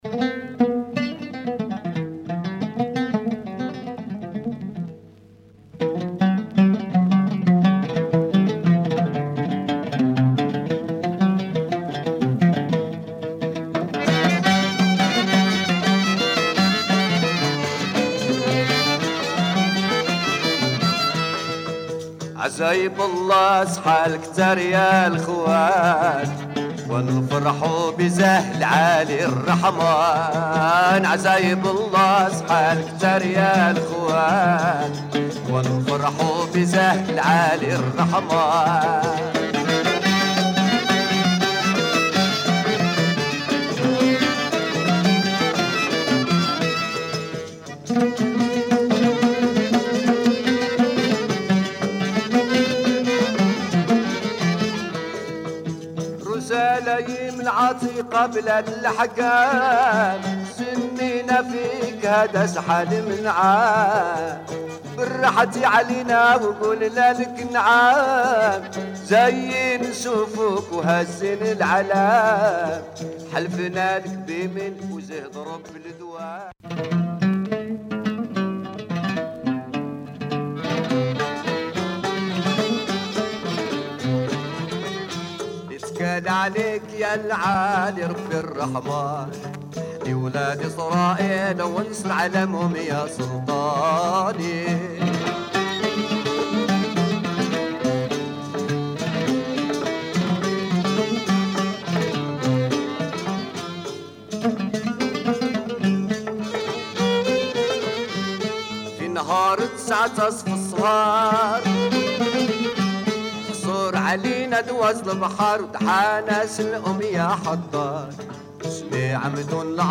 Moroccan song
oud player